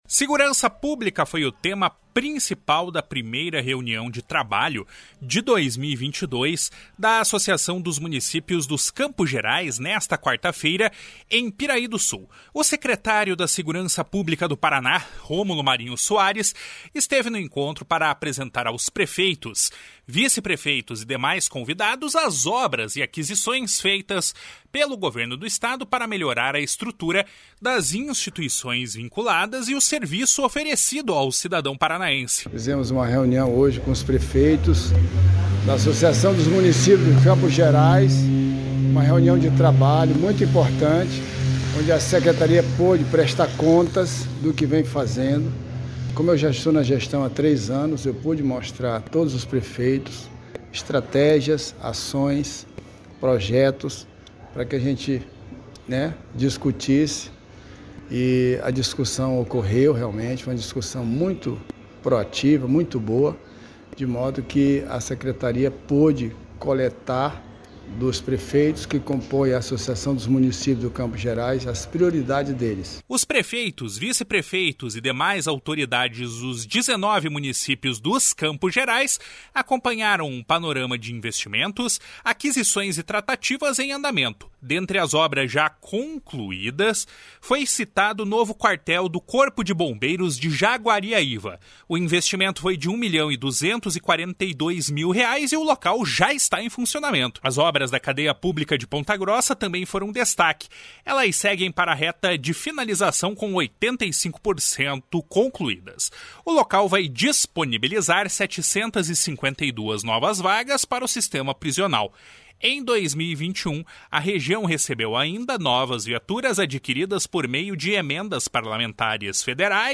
// SONORA ROMULO MARINHO SOARES //